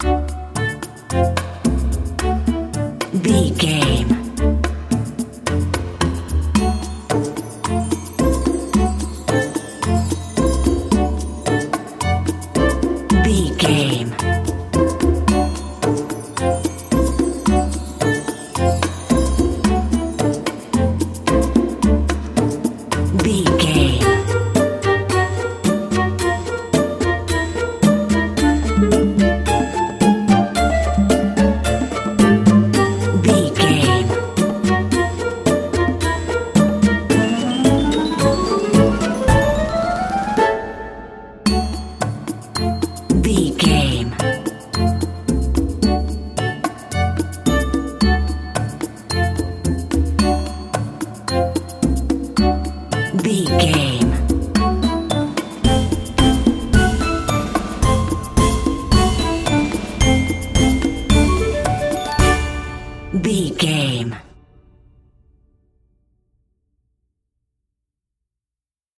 Ionian/Major
D
orchestra
flutes
percussion
conga
oboe
strings
silly
goofy
comical
cheerful
perky
Light hearted
quirky